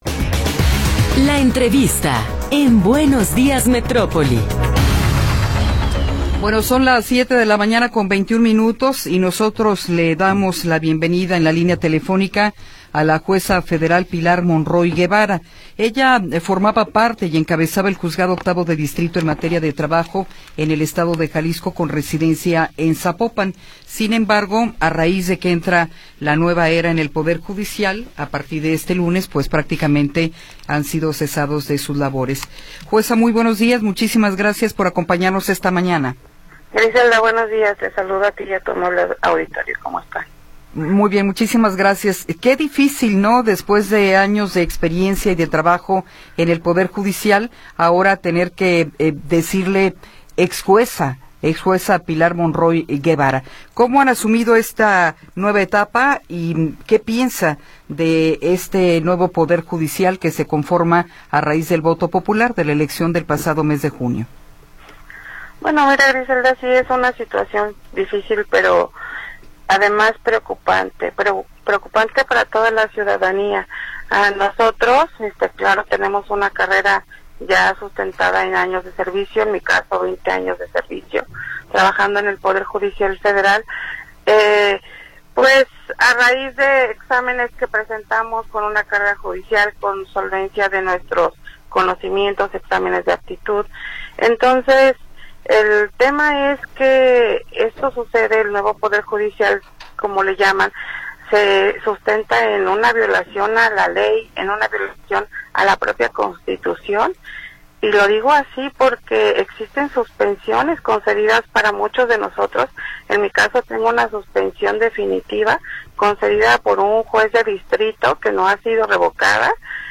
Entrevista con Pilar Monroy Guevara
Pilar Monroy Guevara, ex jueza federal, nos habla sobre la nueva era del poder judicial.